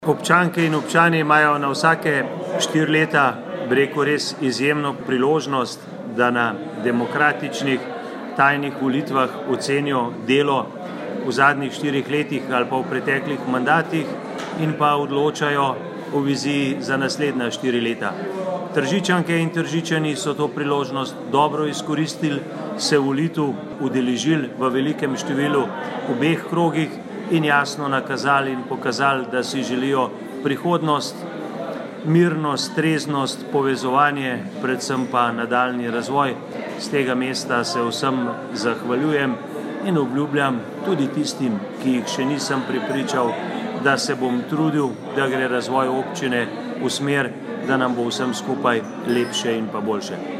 izjava_mag.borutsajoviczupanobcinetrzic_volitve.mp3 (1,2MB)